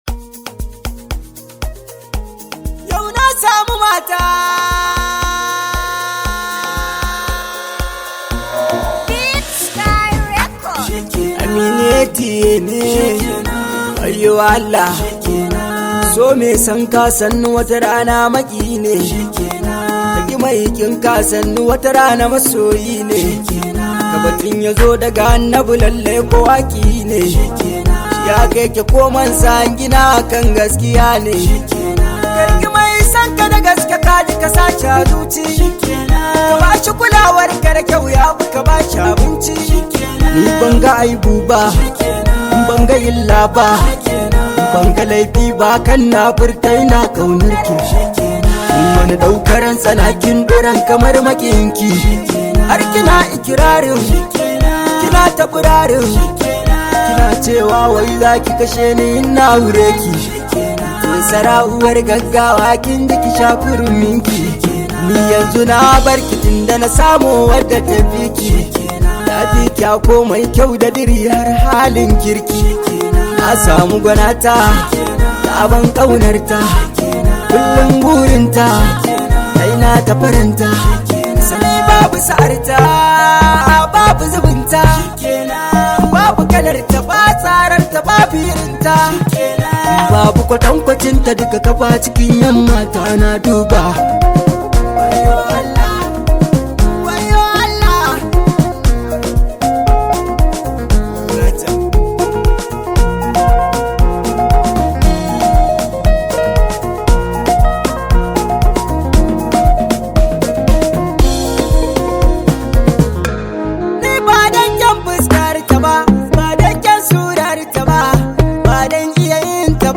Hausa rooted song